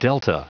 Prononciation du mot delta en anglais (fichier audio)